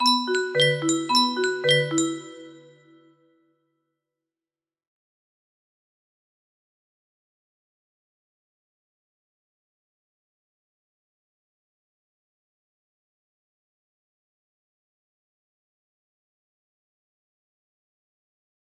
start music box melody